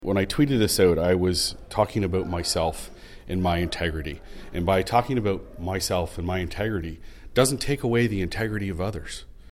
In an interview with Quinte News, Councillor Carr answered.